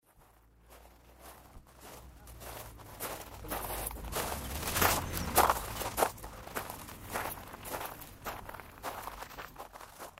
PISADAS EN PICON PISADAS
Ambient sound effects
Pisadas_en_picon_pisadas.mp3